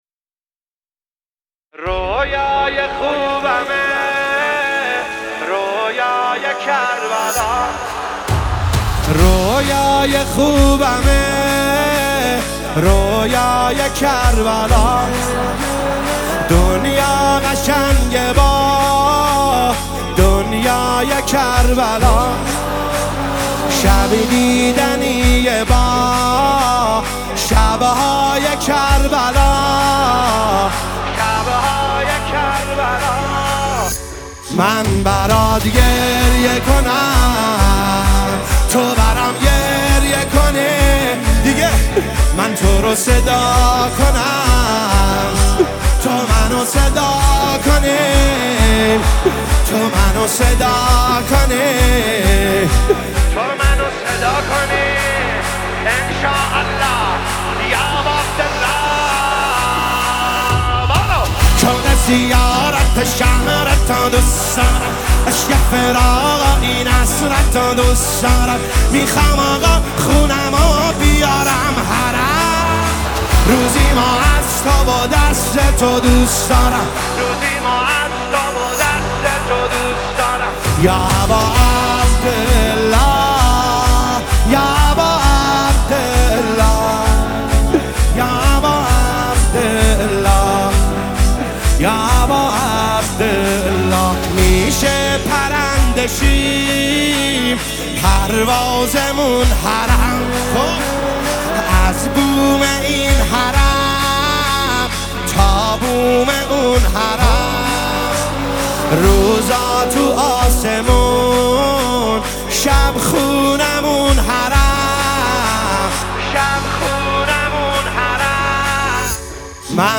با حال و هوای معنوی و شور حسینی دل‌های عاشقان را آرام می‌کند